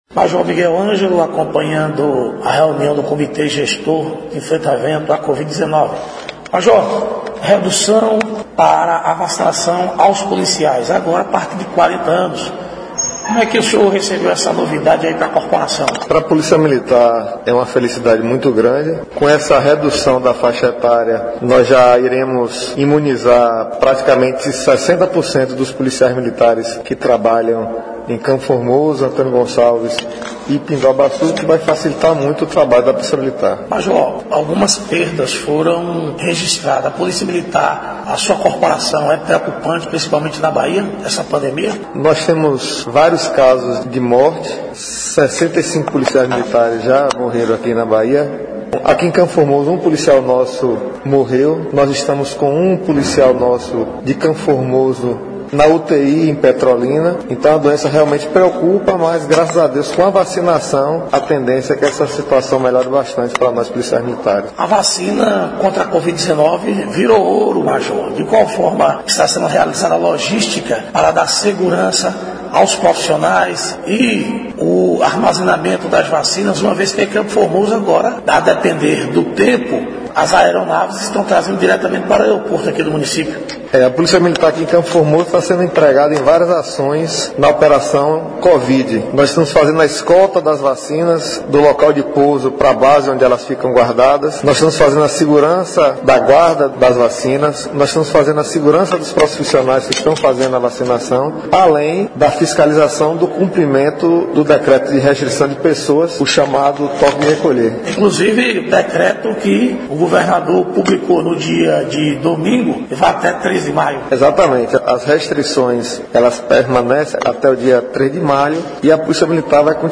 Reportagem: reunião do comitê gestor de enfrentamento da COVID 19